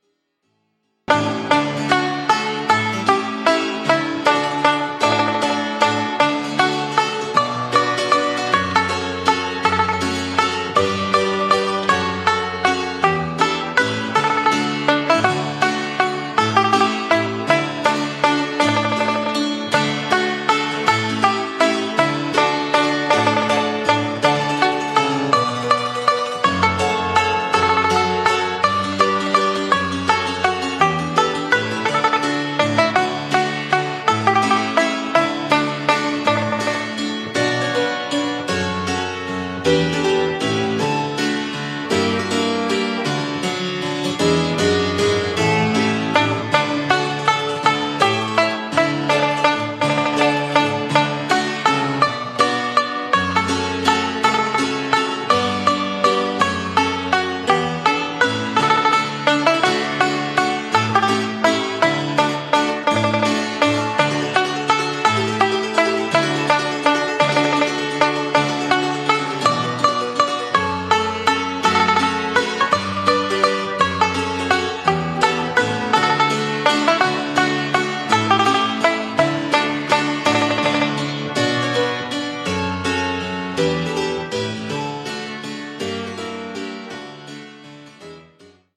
Traditional Folk Song
12-beat intro.
This song is 3/4 waltz time.